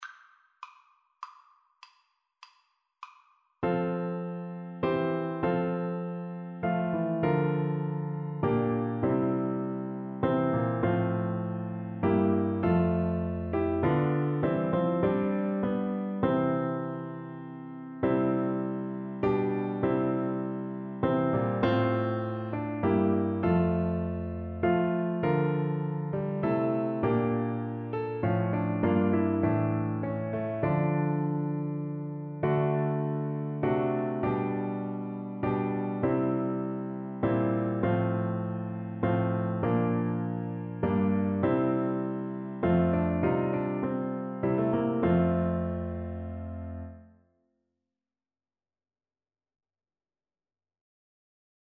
6/4 (View more 6/4 Music)
Classical (View more Classical Flute Music)